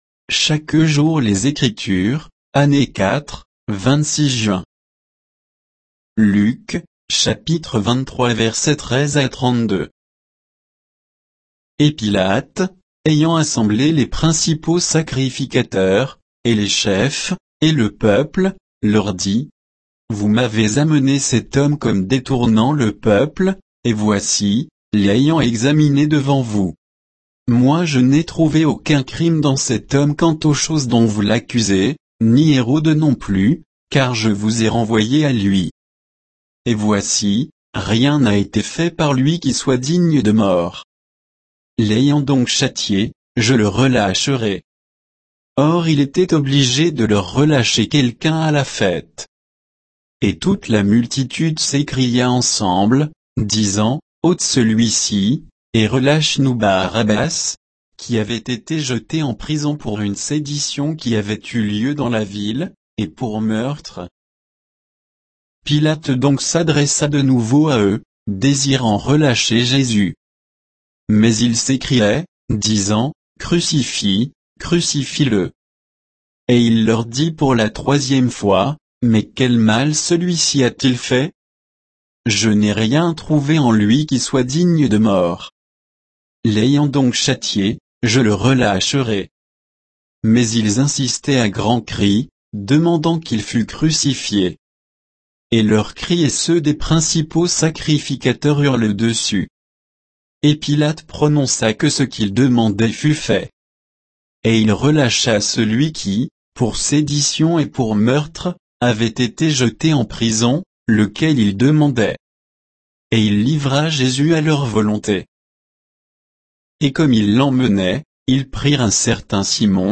Méditation quoditienne de Chaque jour les Écritures sur Luc 23, 13 à 32